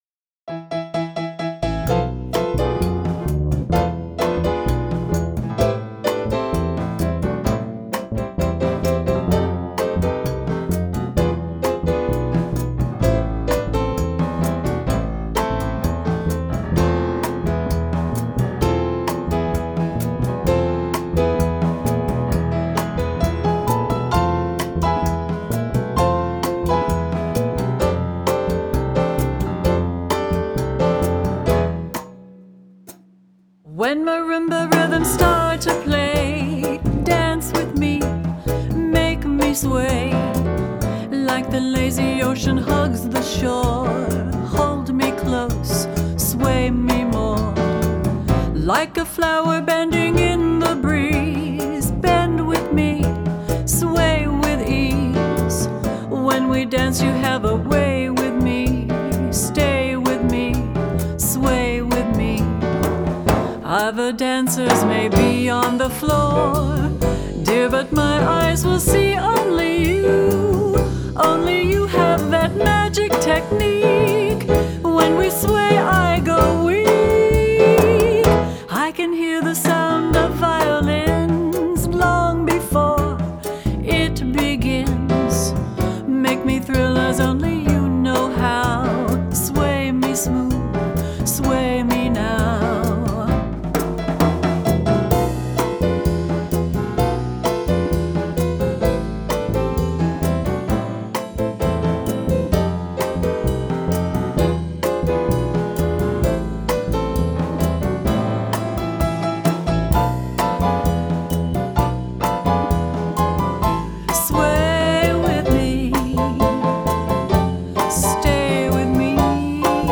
Suite 16 Recording Studio